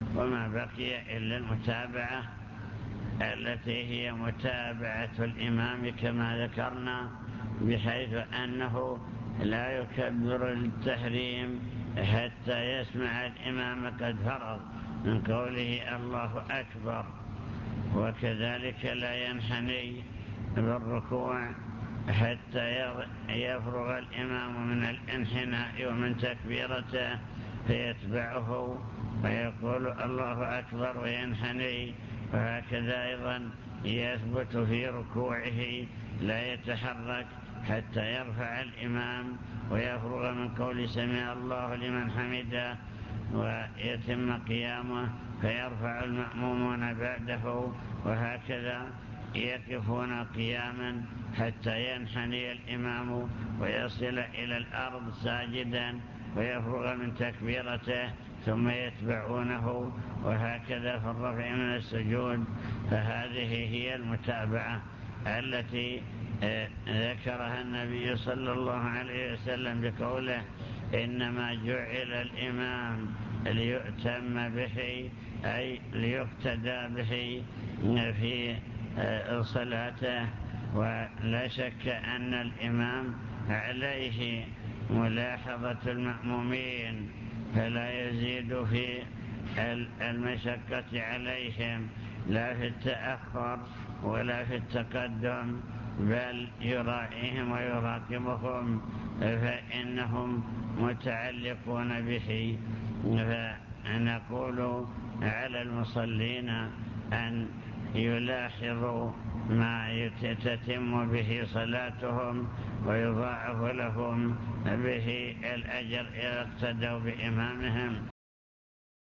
المكتبة الصوتية  تسجيلات - محاضرات ودروس  محاضرة في بدر بعنوان: وصايا عامة